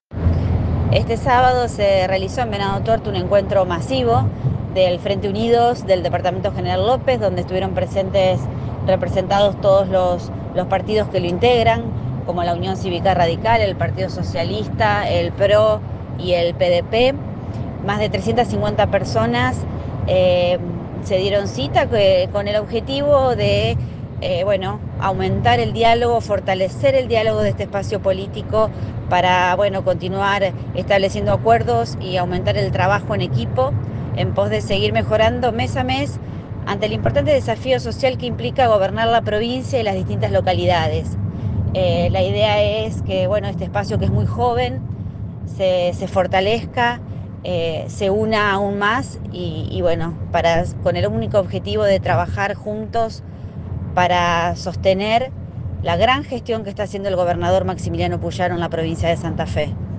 Declaraciones de la Senadora Provincial Leticia Di Gregorio